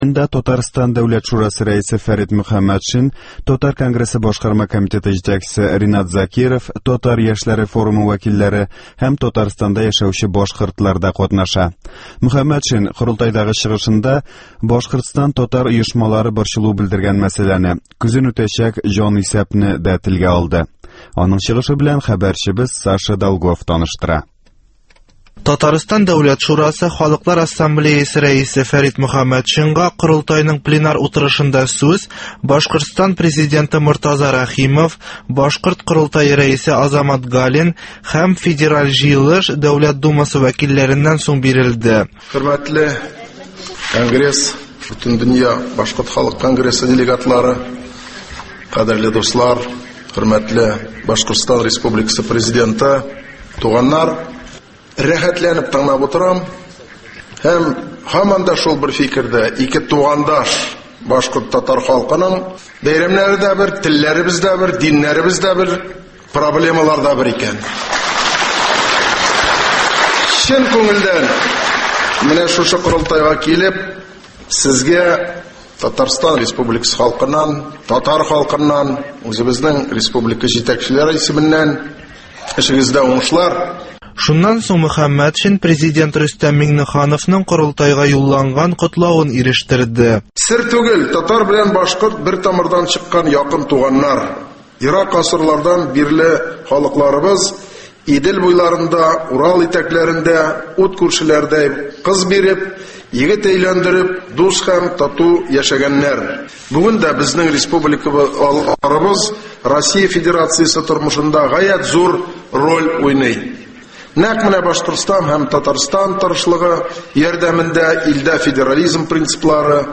Башкорт корылтаенда Фәрит Мөхәммәтшин чыгышы